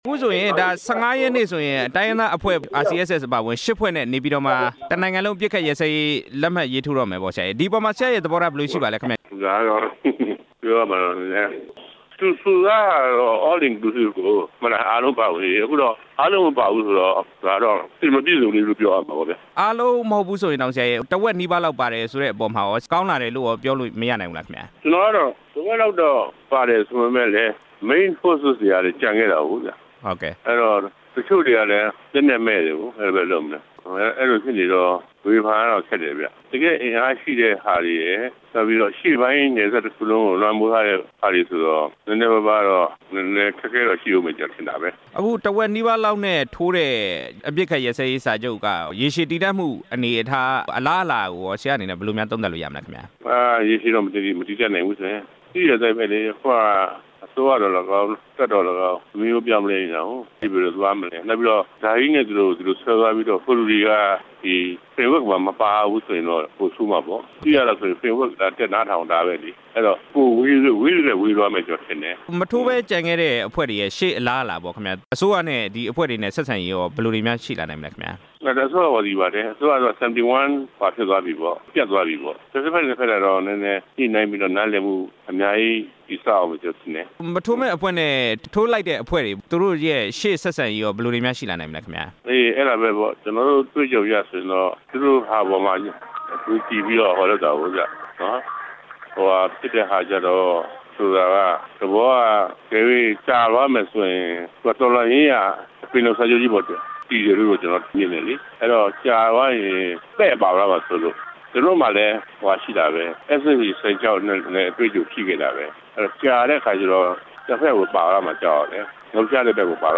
ဦးခွန်ထွန်းဦးနဲ့ မေးမြန်းချက်